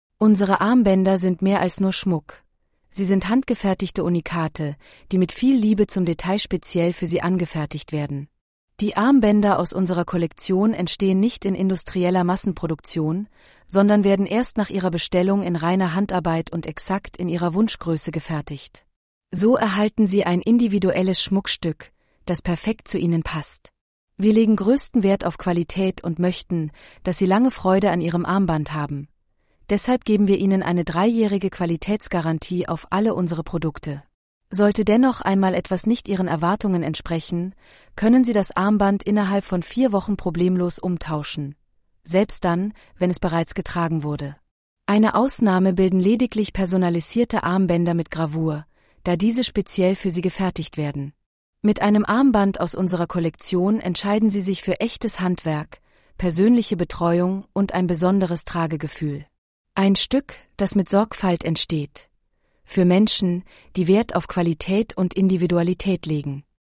unsere-Armbaender-ttsreader.mp3